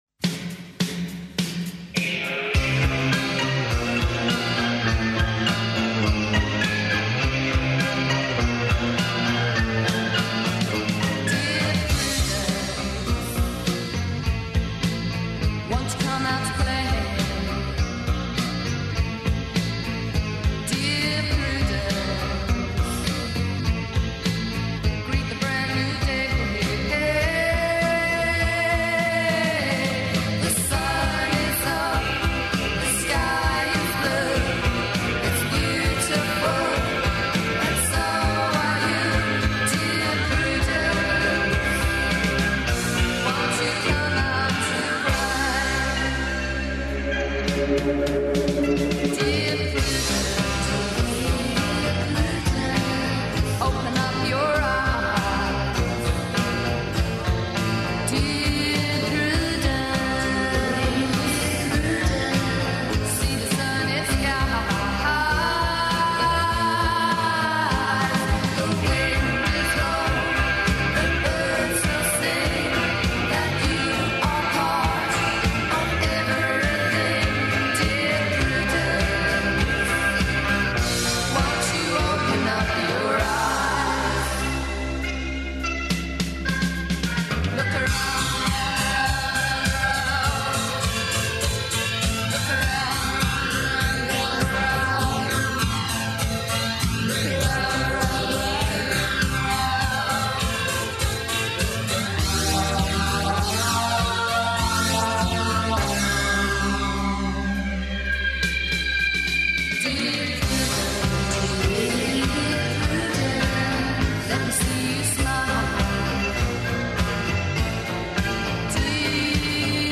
Преслушавамо њихов нови макси сингл.
Vrooom је једна од најзначајнијих електро-рок група у Србији.